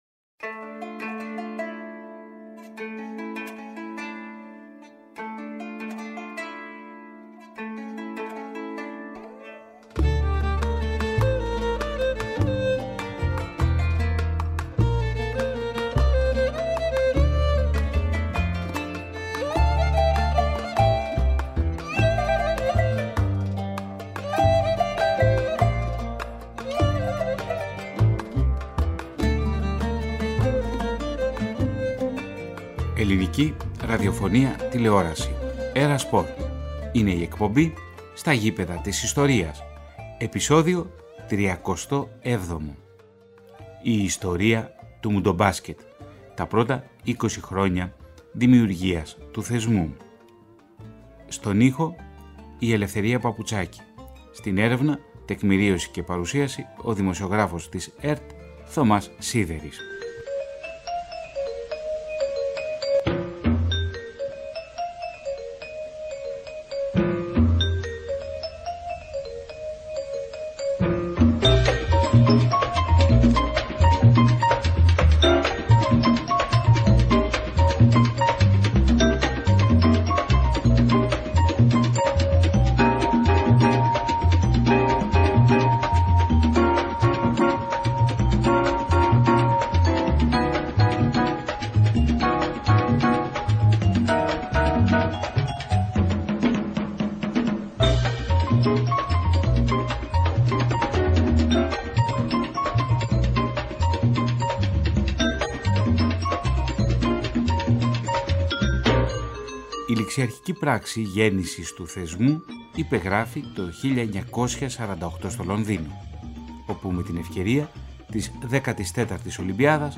Η εκπομπή της ΕΡΑ ΣΠΟΡ στα “ΓΗΠΕΔΑ ΤΗΣ ΙΣΤΟΡΙΑΣ” παρουσιάζει ένα ραδιοφωνικό ντοκιμαντέρ για την ιστορία του Παγκοσμίου Κυπέλλου Μπάσκετ τα πρώτα είκοσι χρόνια της διοργάνωσης.